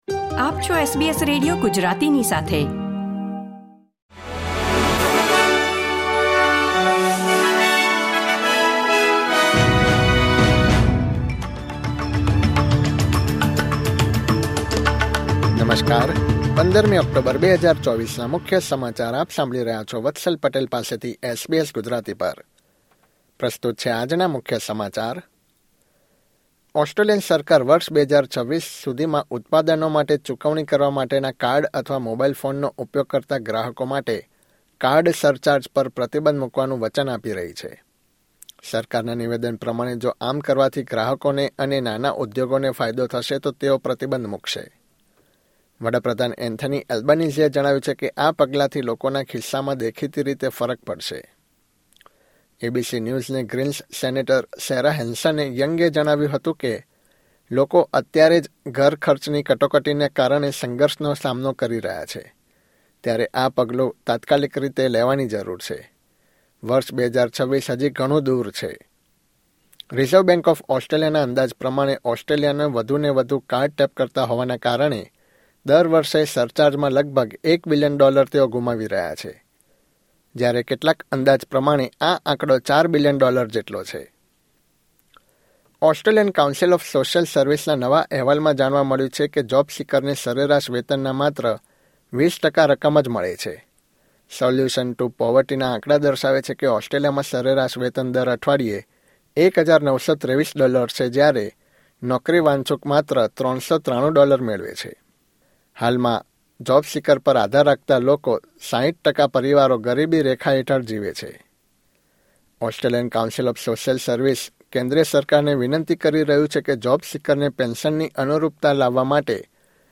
SBS Gujarati News Bulletin 15 October 2024